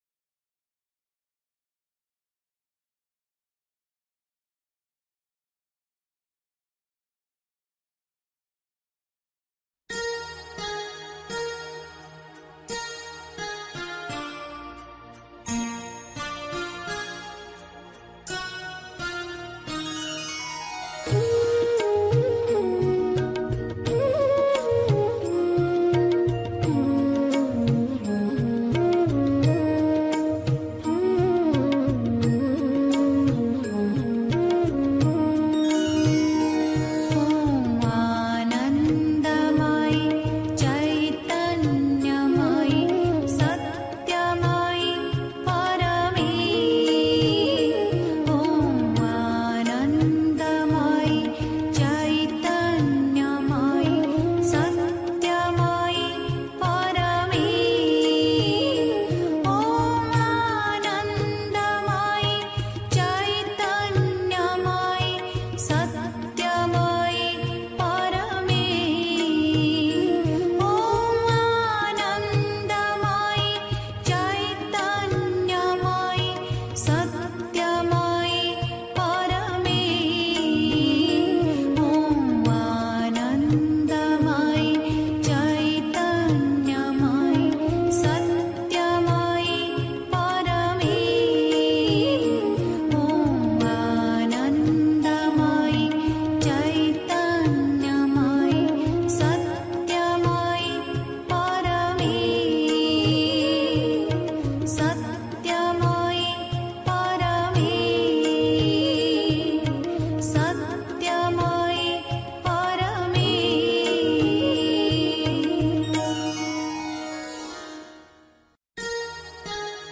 1. Einstimmung mit Musik. 2. Aus den Worten herauskommen und mit dem Wirken beginnen (Die Mutter, CWM Vol 4, pp. 64-65) 3. Zwölf Minuten Stille.